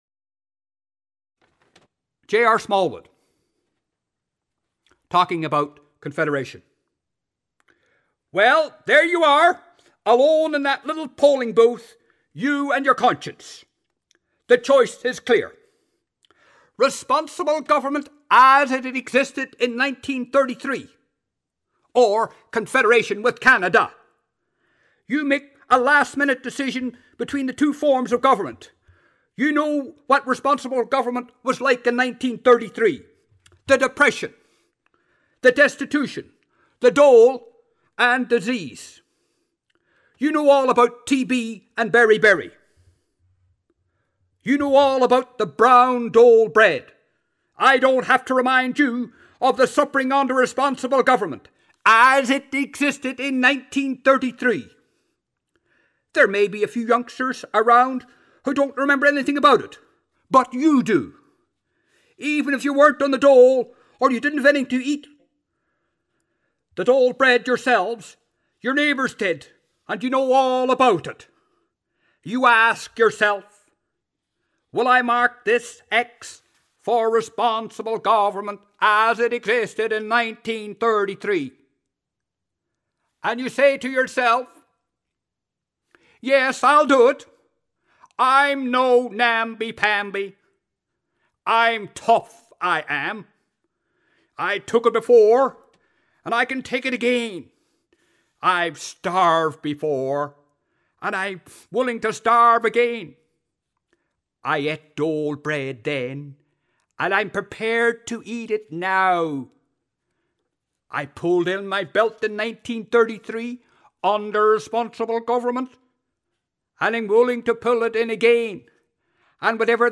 Speech #3: J.R. Smallwood speaking for Confederation